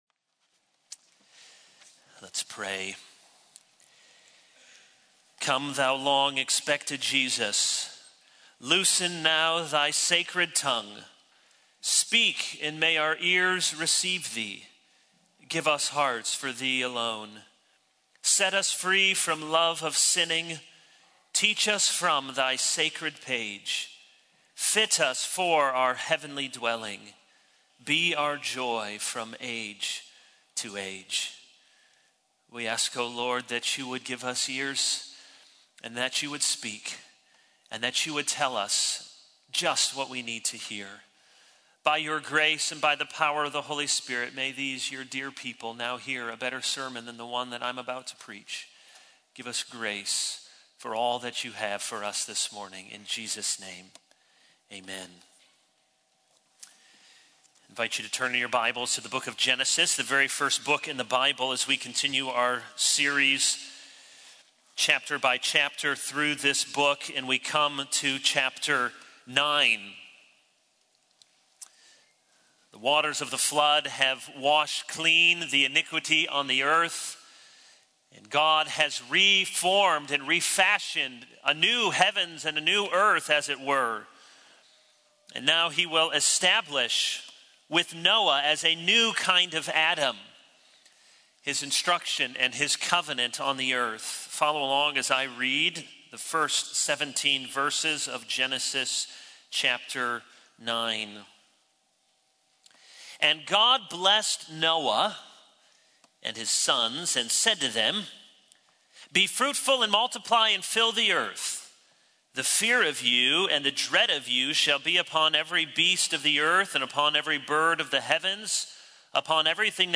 All Sermons A Prayer of Confession 0:00 / Download Copied!
Pastor Kevin DeYoung